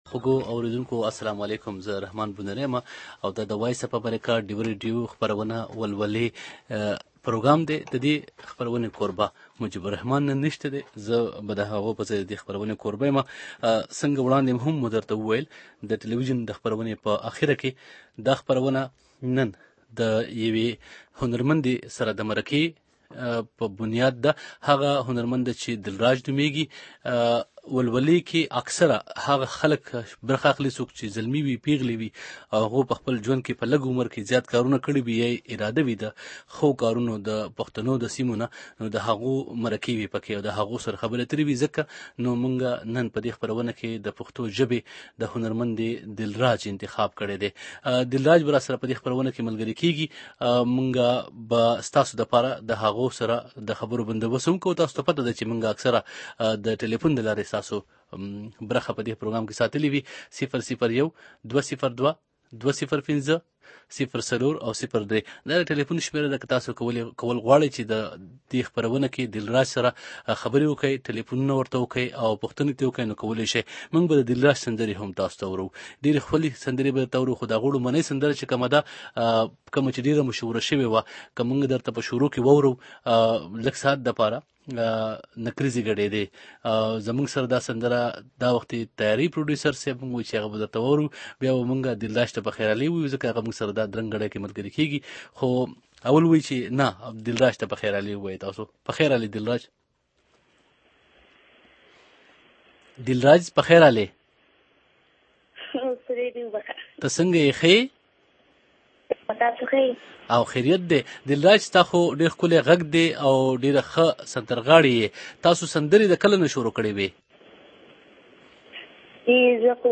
دپښتو پېغله سندرغاړې په ولولې پروگرام کې